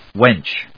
/wéntʃ(米国英語), wentʃ(英国英語)/